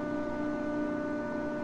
whirr.ogg